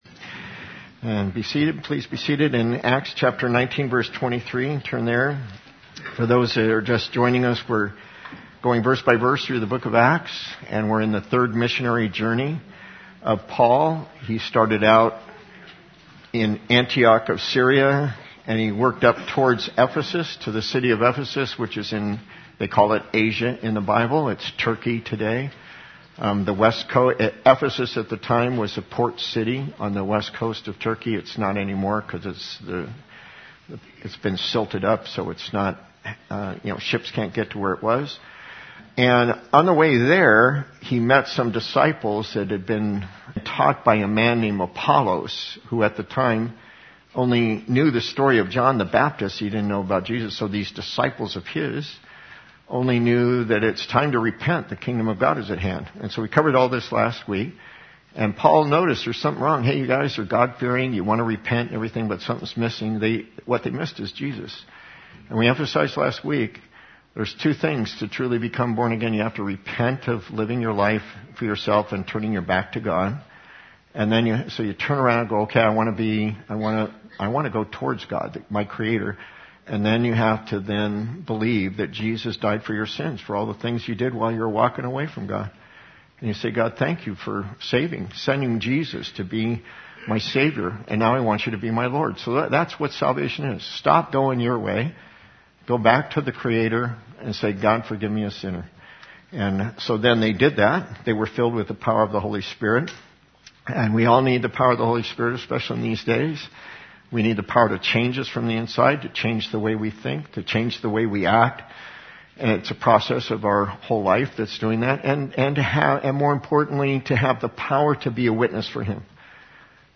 The audio file includes communion service at the end.